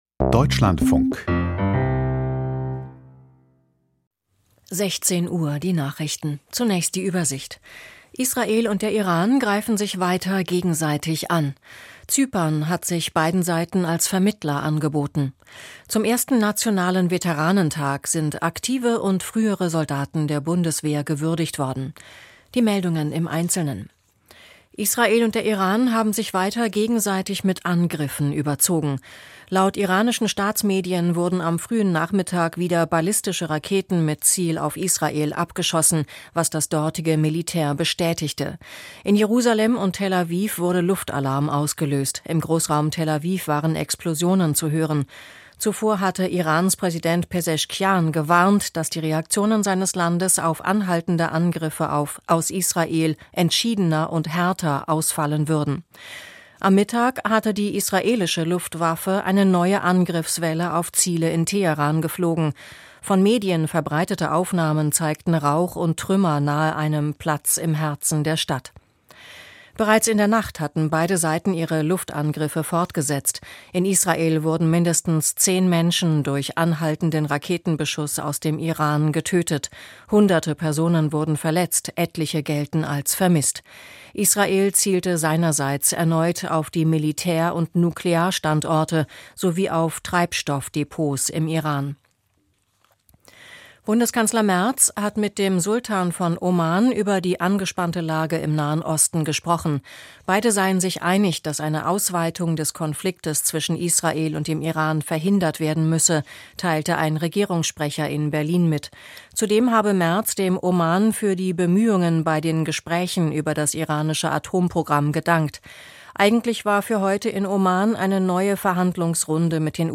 Die Nachrichten vom 15.06.2025, 16:00 Uhr
Die wichtigsten Nachrichten aus Deutschland und der Welt.